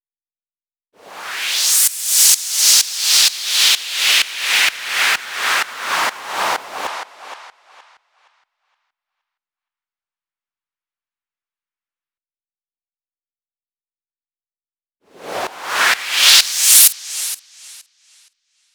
VTDS2 Song Kit 17 Pitched Your Life FX Noiz.wav